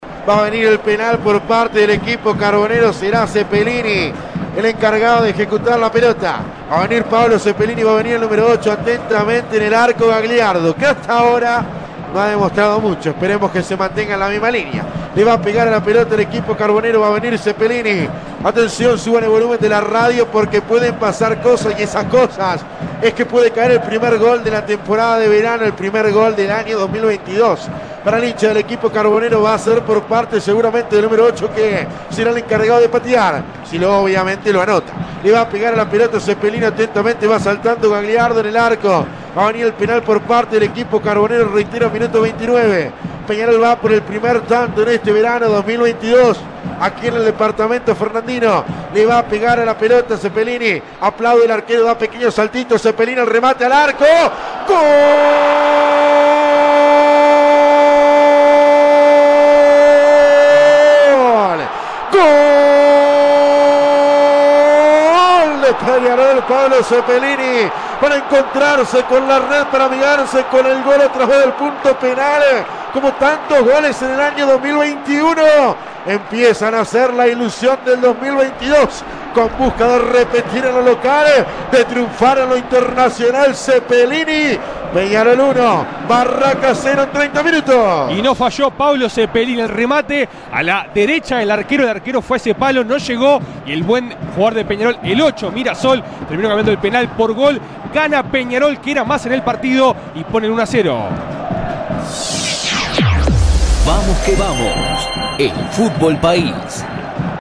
Relato Vamos que vamos: